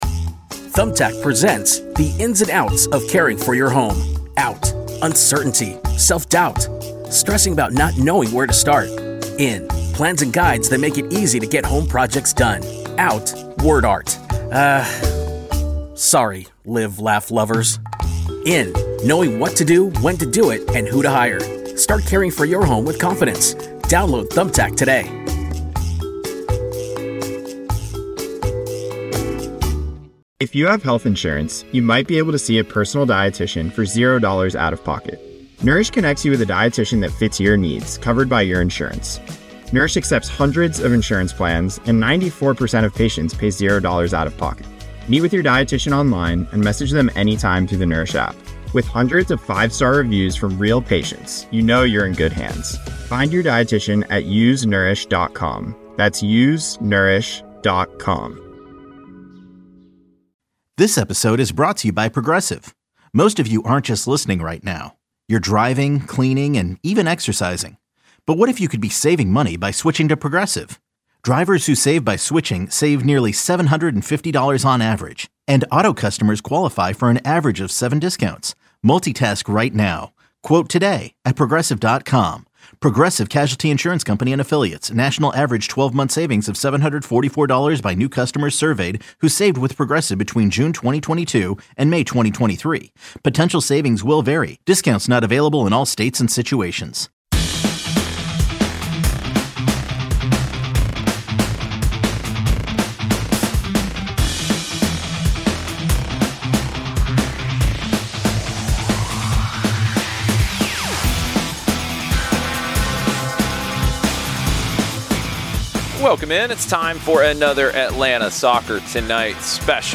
Sports Radio 92-9 The Game coverage of Atlanta United and MLS with weekly podcasts and interviews including Atlanta United players and executives.